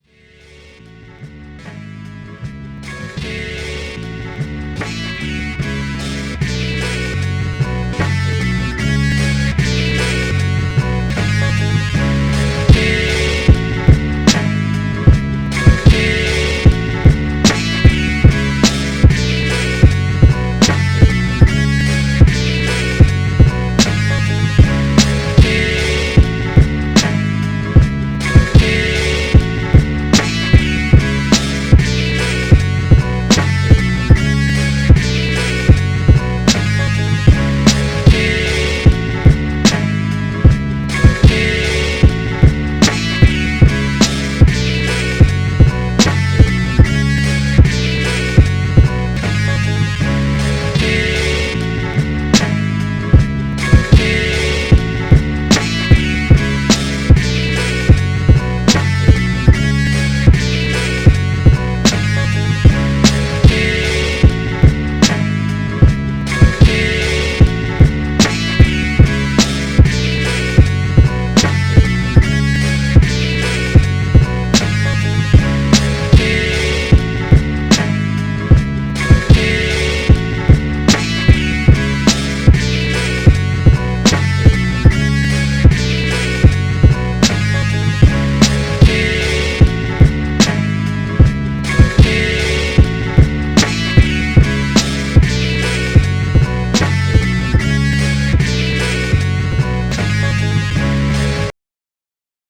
Boom Bap Instrumentals